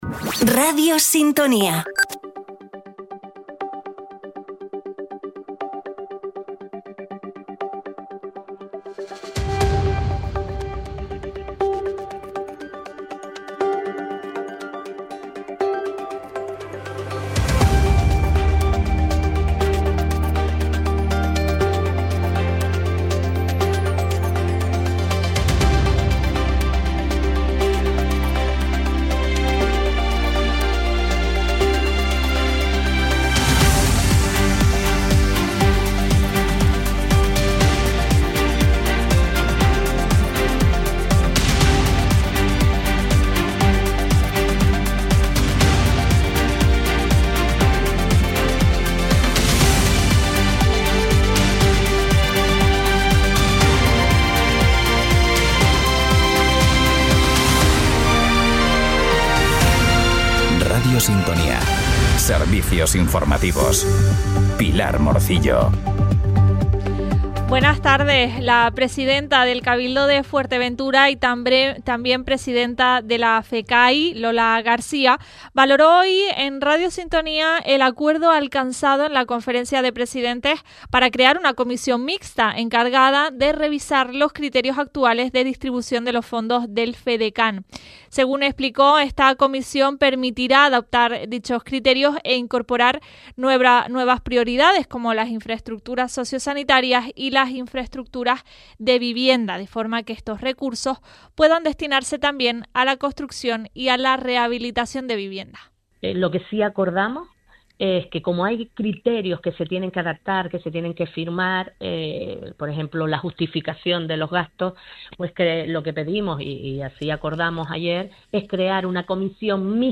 En él te contamos, en directo, las noticias más importantes de la jornada, a partir de las 13:15h.